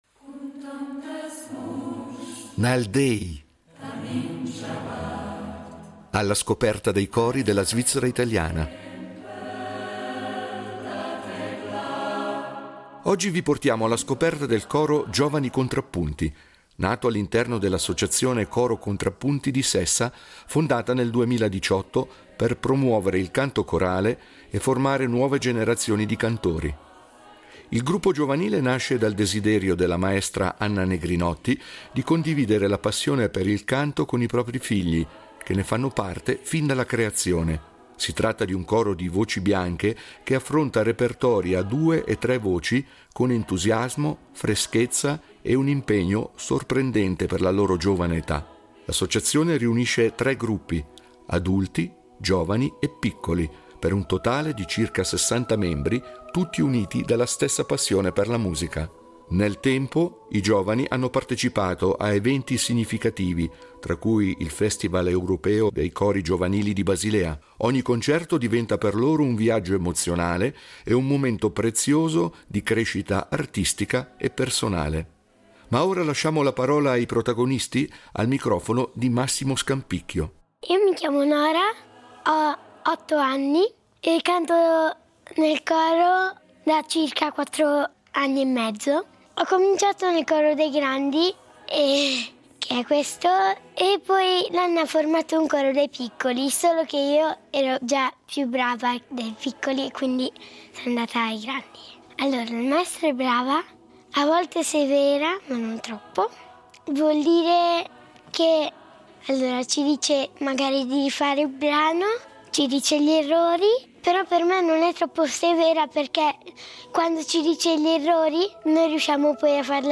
Nal déi, cori della svizzera italiana
è un coro di voci bianche che affronta repertori a due e tre voci con entusiasmo